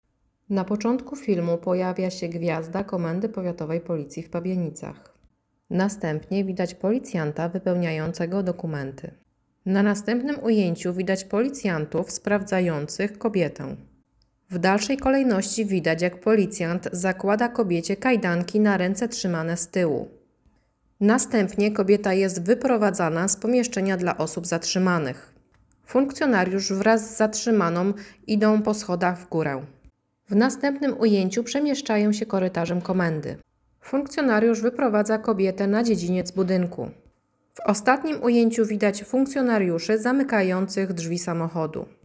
Nagranie audio Audiodeskrypcja nagrania